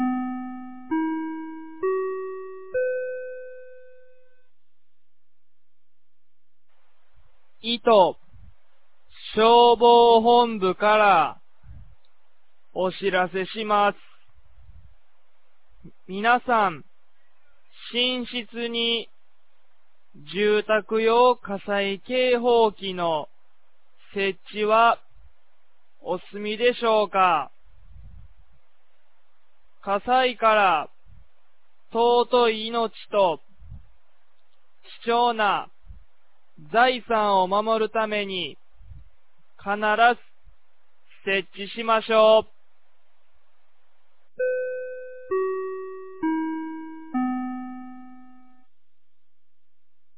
2024年09月24日 10時01分に、九度山町より全地区へ放送がありました。
放送音声